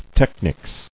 [tech·nics]